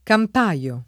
DOP: Dizionario di Ortografia e Pronunzia della lingua italiana